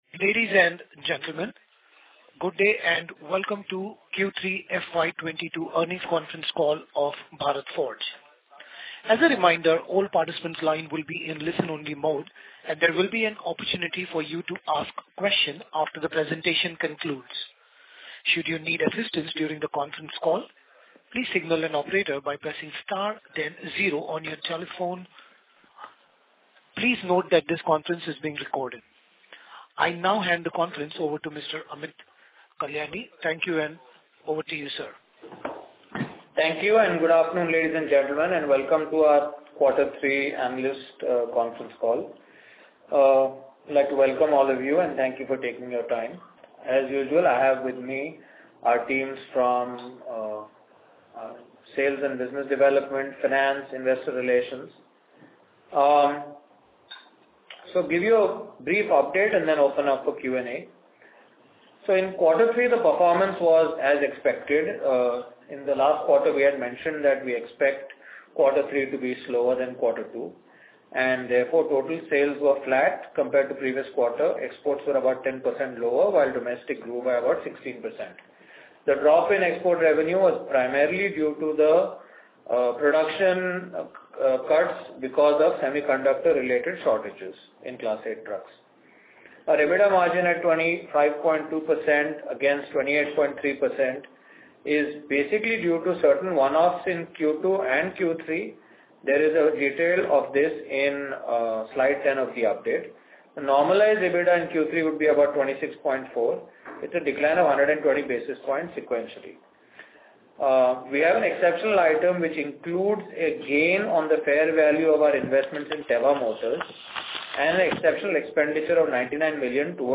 Bharat Forge - Analyst Conference Calls